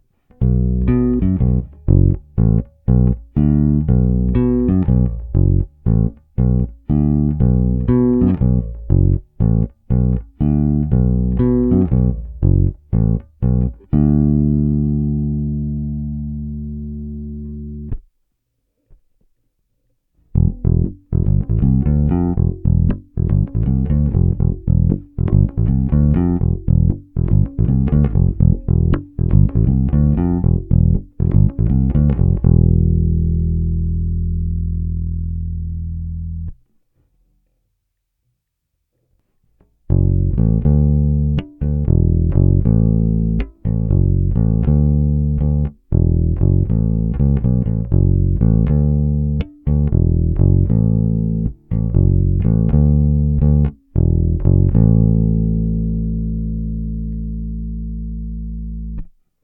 Následující nahrávky jsou nahrávány přímo do zvukovky, bez jakýchkoli úprav (mimo normalizace).
Nahráno na skládačku precision, struny La Bella Deep Talkin Bass flatwounds, tónová clona otevřená.
Basy 10, výšky 10